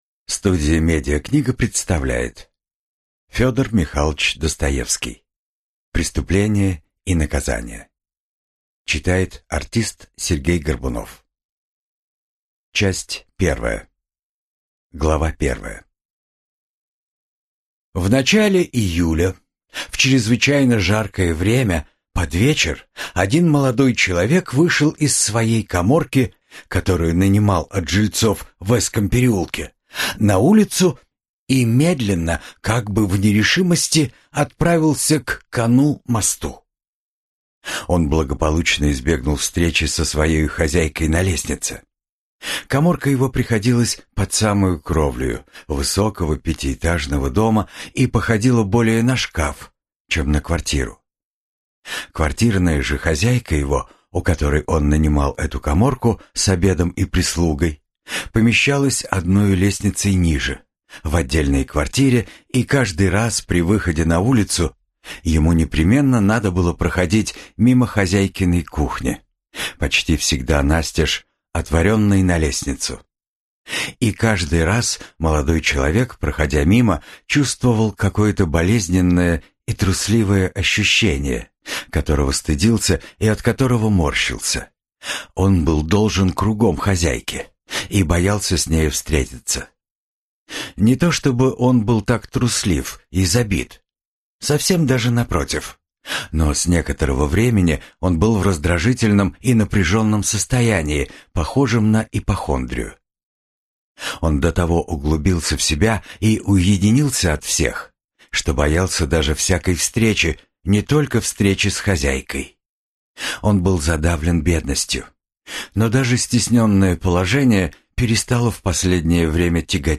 Аудиокнига Преступление и наказание | Библиотека аудиокниг